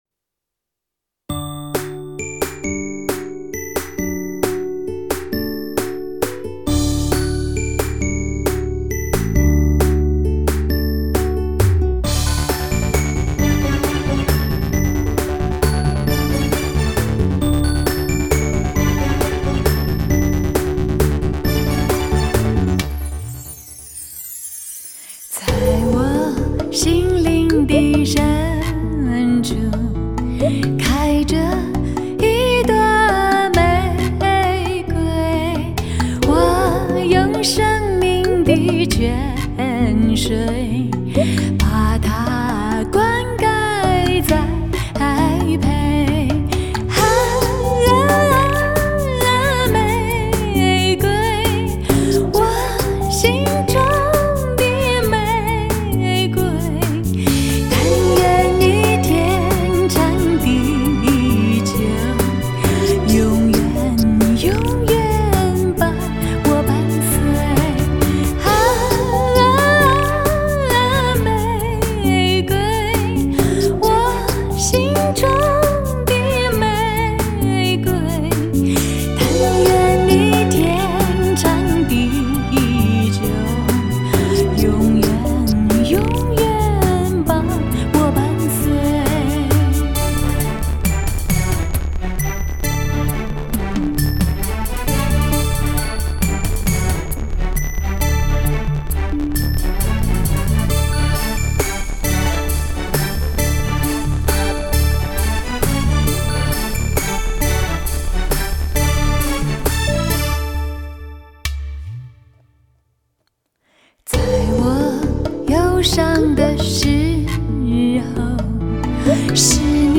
专辑曲风是“新民歌”